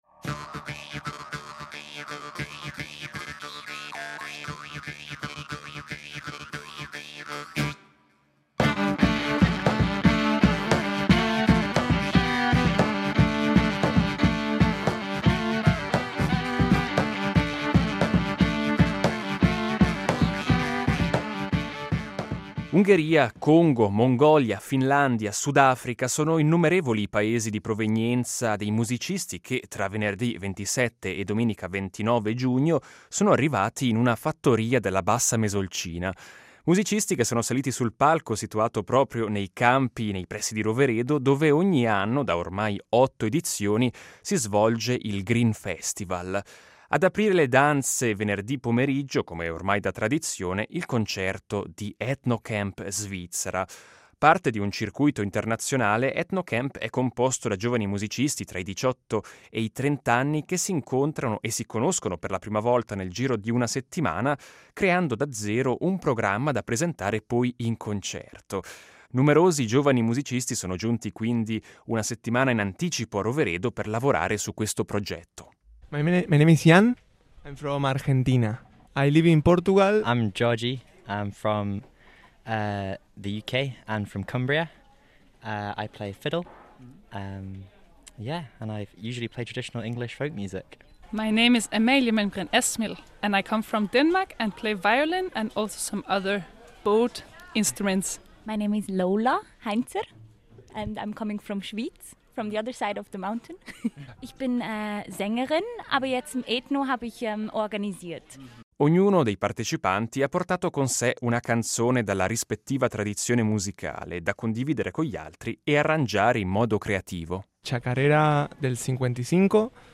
Con le voci di alcuni dei talentuosi musicisti
Abbiamo seguito e registrato diversi concerti e intervistato gli innumerevoli musicisti che hanno preso parte all’edizione 2025 del Grin Festival. Vi proponiamo allora un resoconto del Grin festival con le voci di alcuni dei talentuosi musicisti che si sono esibiti.